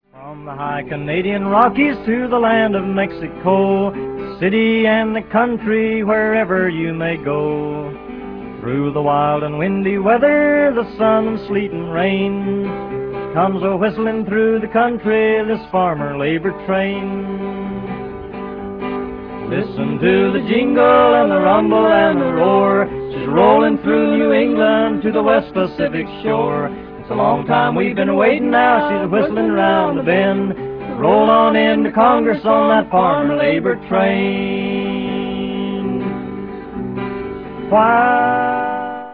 guitar
Recorded in New York between 1944 and 1949.